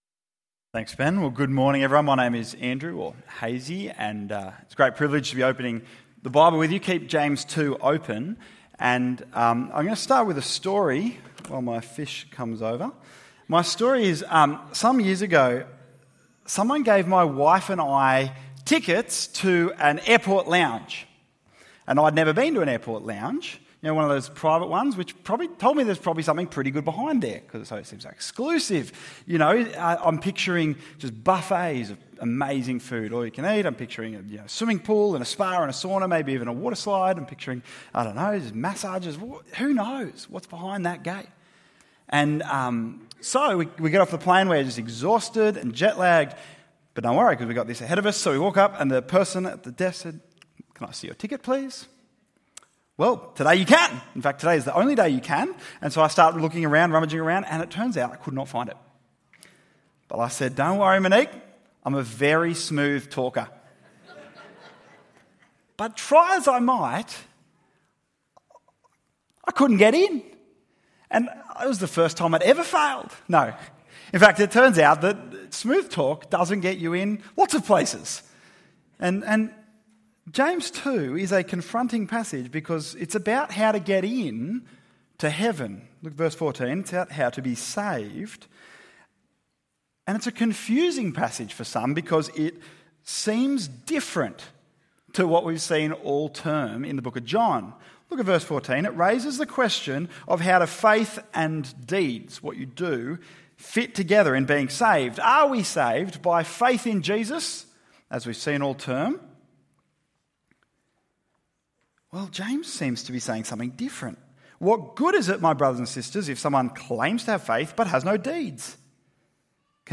Real Faith ~ EV Church Sermons Podcast